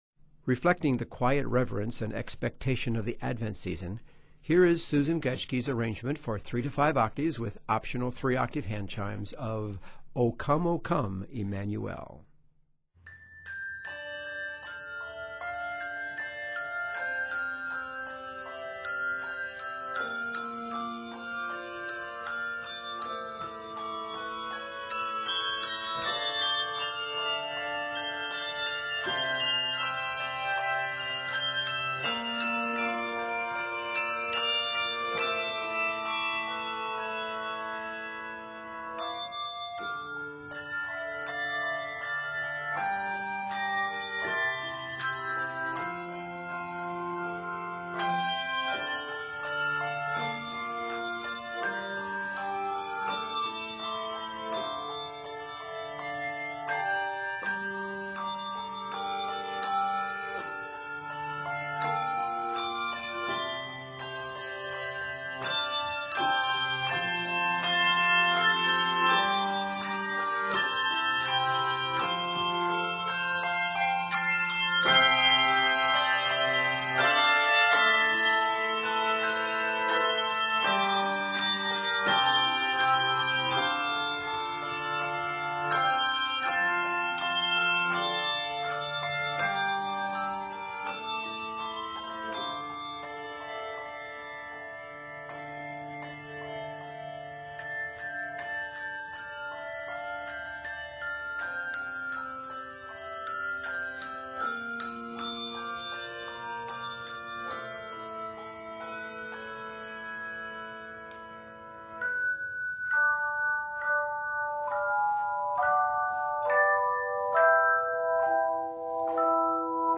optional 3 octave handchimes
is arranged in d minor and is 79 measures